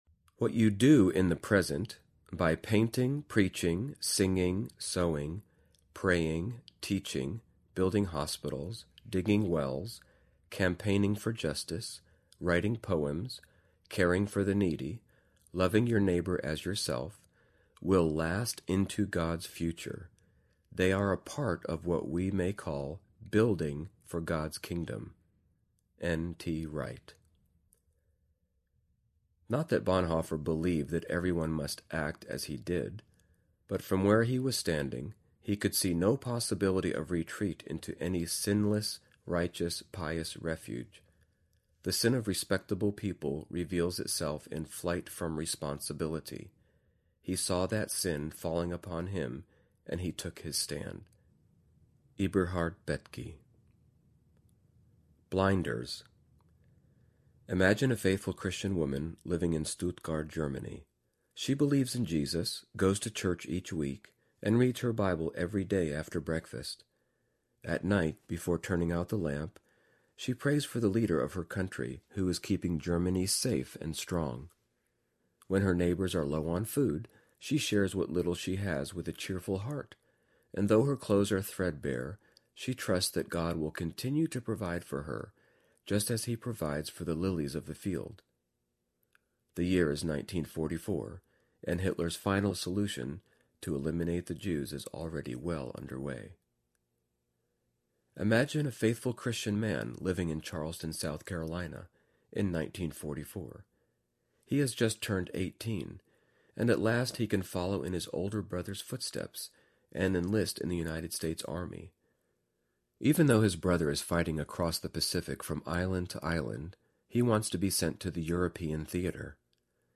Pursuing Justice Audiobook
Narrator
8.4 Hrs. – Unabridged